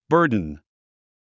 発音
bə’ːrdn　バードゥン